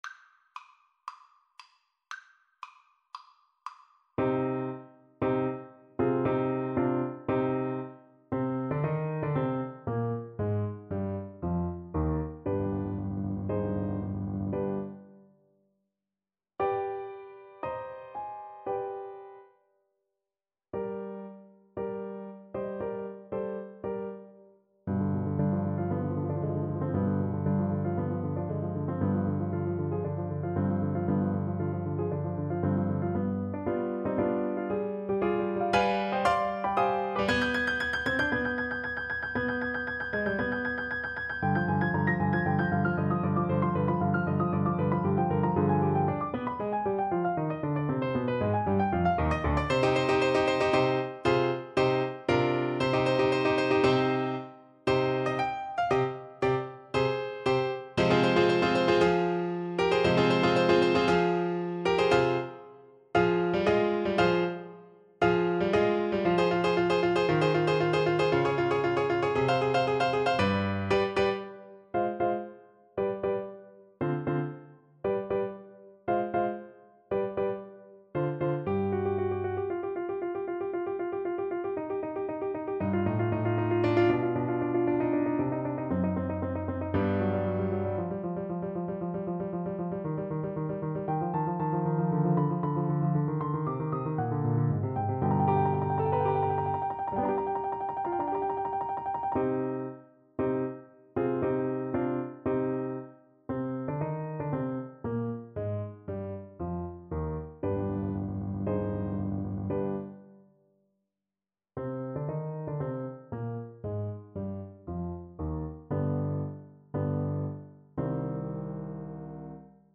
C minor (Sounding Pitch) D minor (Clarinet in Bb) (View more C minor Music for Clarinet )
4/4 (View more 4/4 Music)
Allegro =116 (View more music marked Allegro)